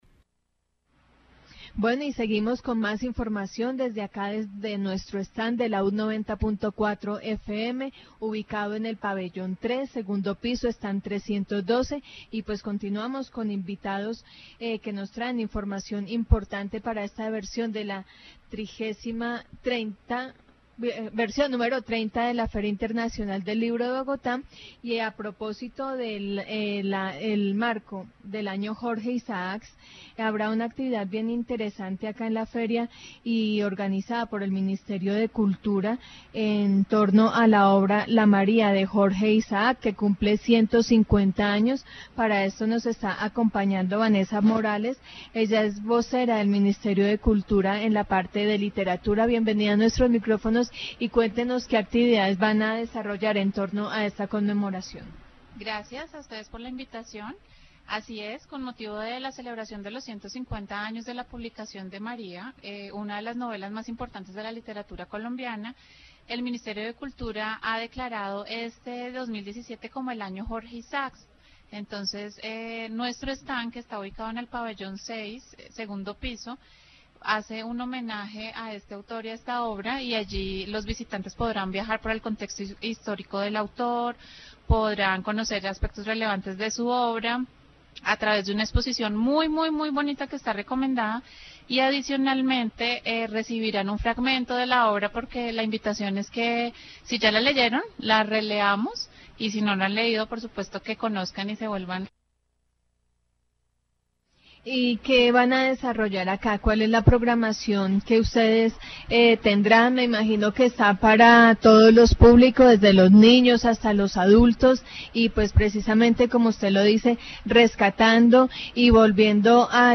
Programas de radio
Feria del Libro 2017